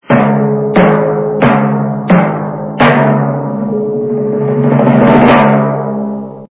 При прослушивании Звук барабана - СМС качество понижено и присутствуют гудки.
Звук Звук барабана - СМС